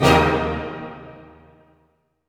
Index of /90_sSampleCDs/Roland L-CD702/VOL-1/HIT_Dynamic Orch/HIT_Orch Hit Maj
HIT ORCHM05R.wav